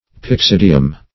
Pyxidium \Pyx*id"i*um\, n.; pl. Pyxidia.